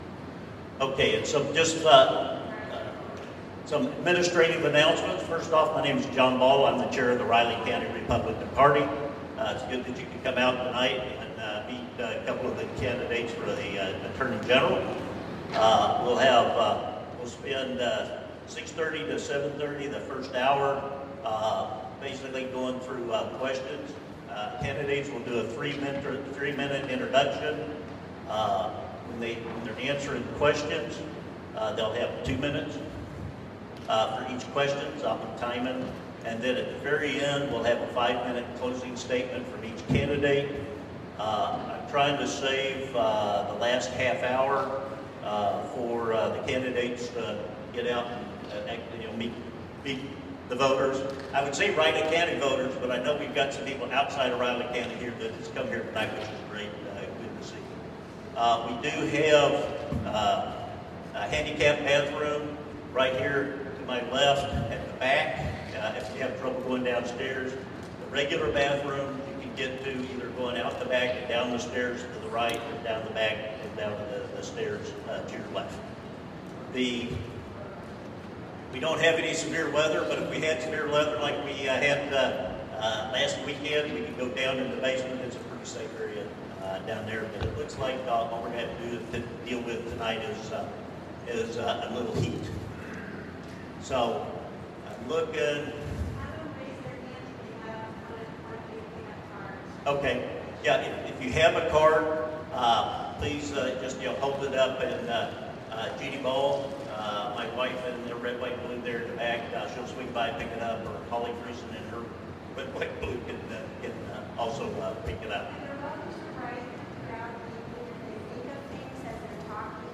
Riley County Republicans Saturday hosted two of three candidates to be the party’s nominee in the race for attorney general in a town hall forum and meet and greet in the Wareham Opera House.